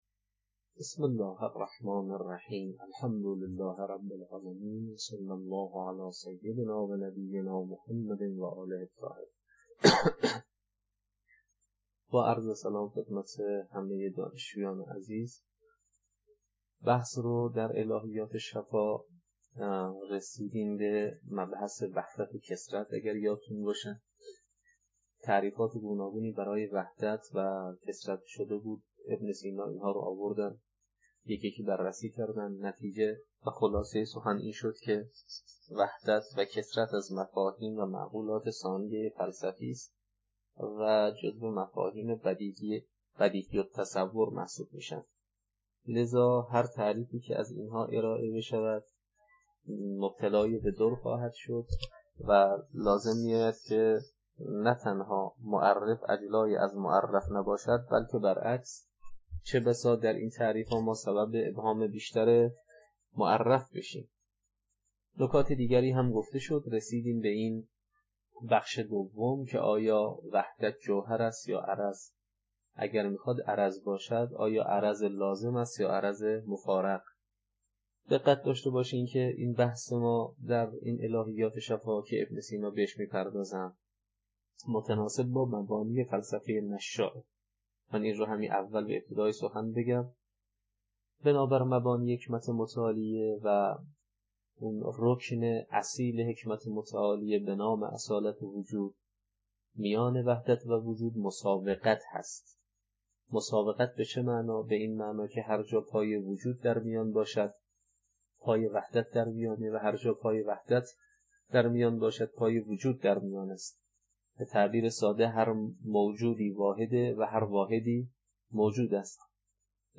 الهیات شفاء، تدریس